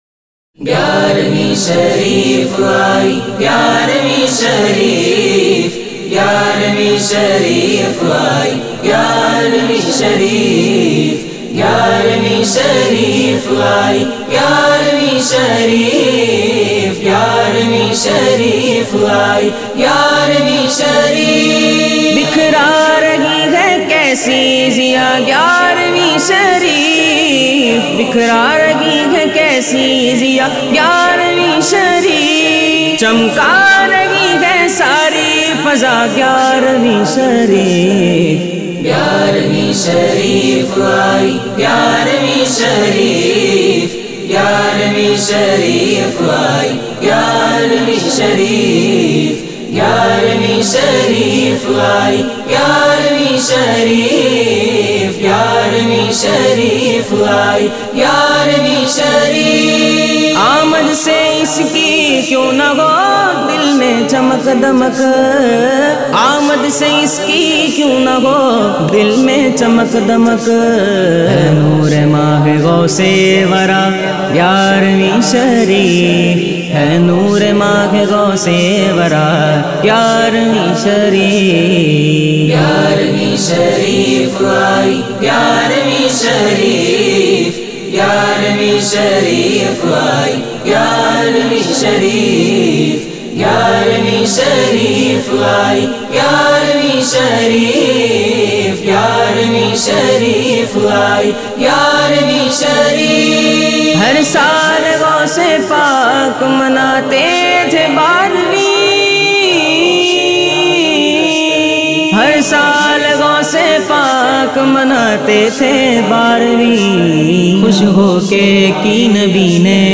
منقبت